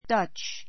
Dutch dʌ́tʃ ダ チ 形容詞 オランダの; オランダ人[語]の 関連語 the Netherlands （オランダ） go Dutch go Dutch go Dutch （食事の費用を） 割り勘 かん にする ⦣ ふつうは split the cost [the bill] という.